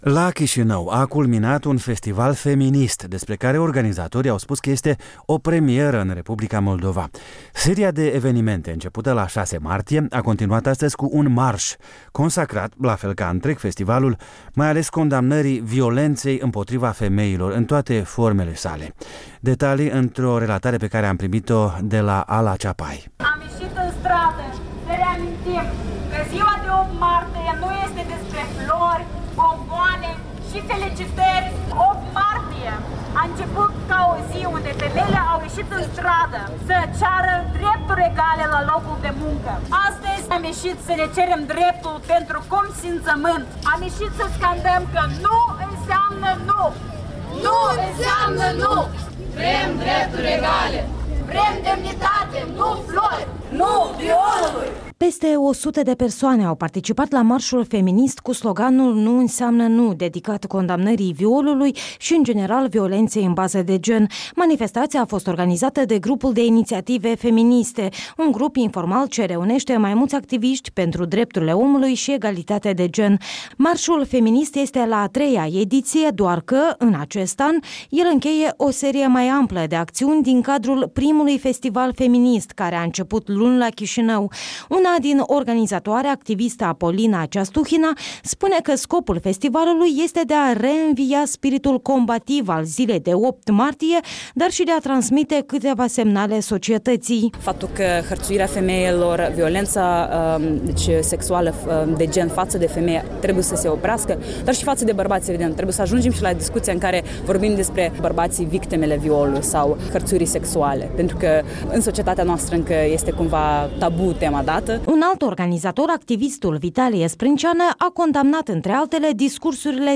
Marșul contra violenței de la Chișinău